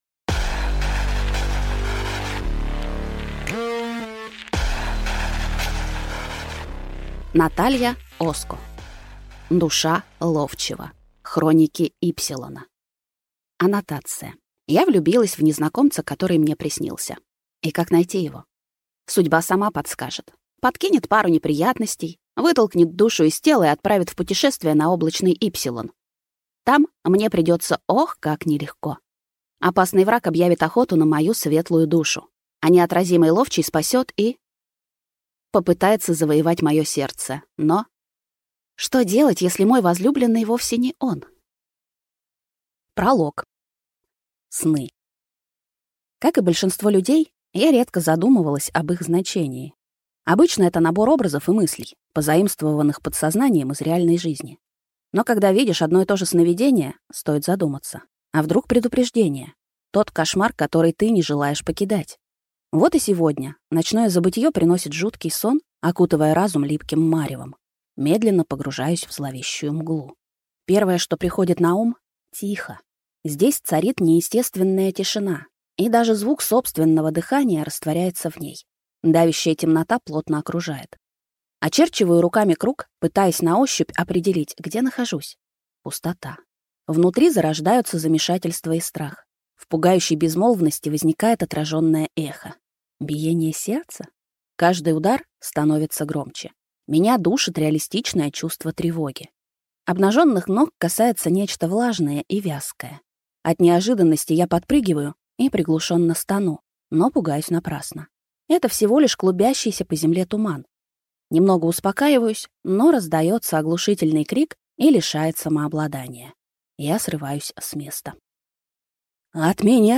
Аудиокнига Душа ловчего. Хроники Ипсилона | Библиотека аудиокниг